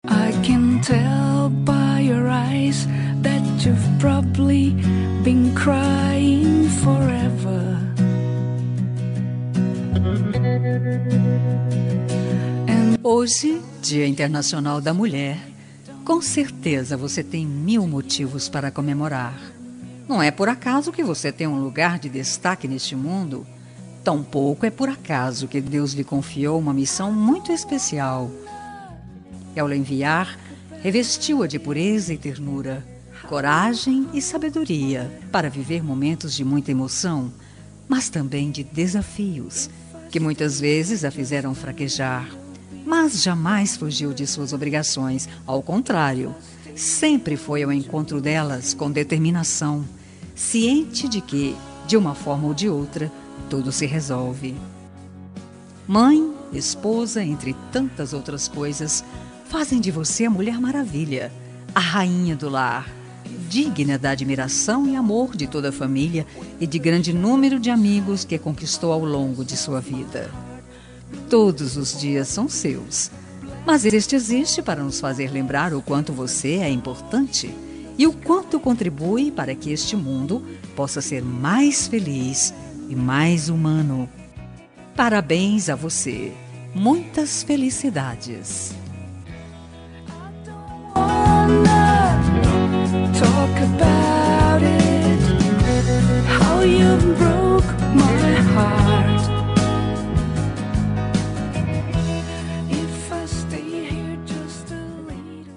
Dia das Mulheres Neutra – Voz Feminina – Cód: 5266